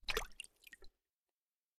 8abddf23c7 Divergent / mods / Bullet Shell Sounds / gamedata / sounds / bullet_shells / generic_water_5.ogg 38 KiB (Stored with Git LFS) Raw History
generic_water_5.ogg